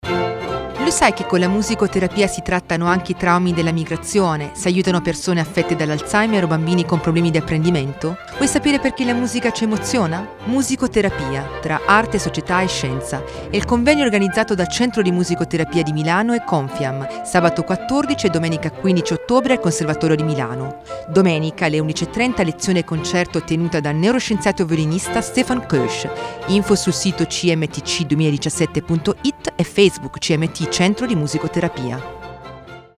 spot-Radio-Popolare.mp3